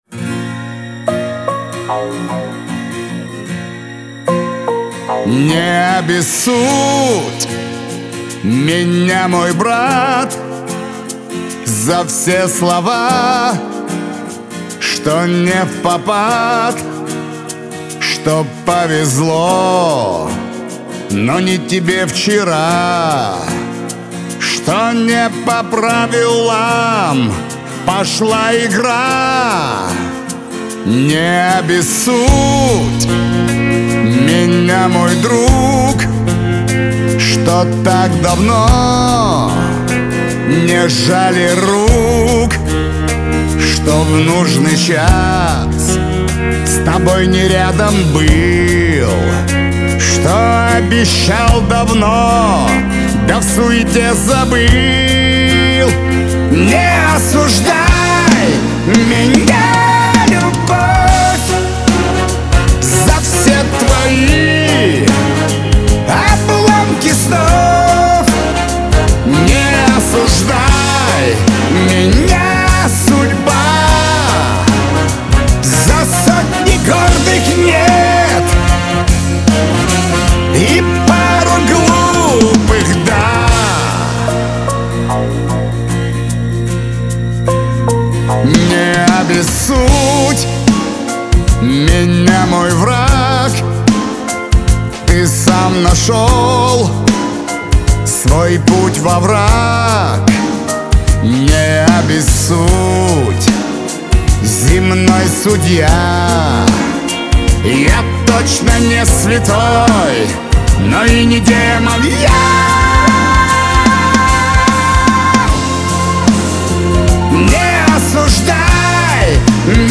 эстрада
Шансон
Лирика , pop